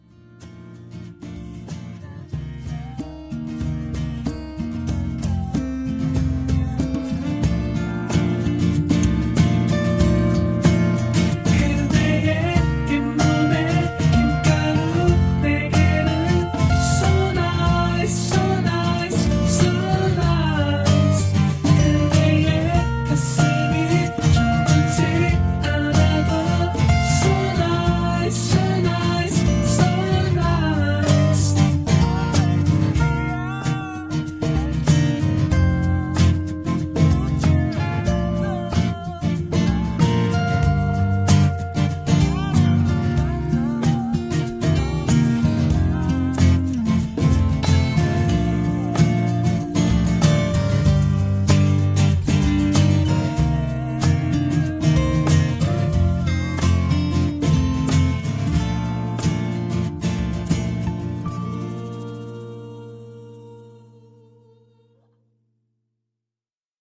음정 -1키 3:29
장르 가요 구분 Voice MR